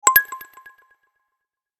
Notification-bell-delayed-ding.mp3